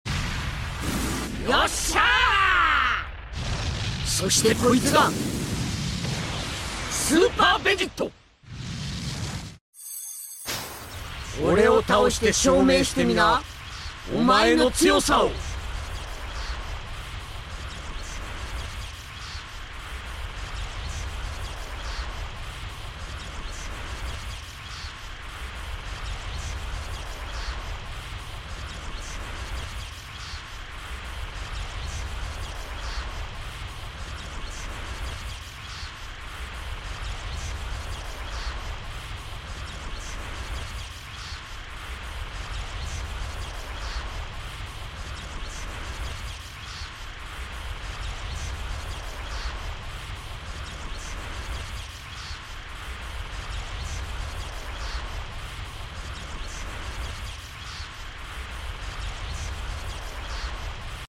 Ultra Super Vegito Animation Japenese Dub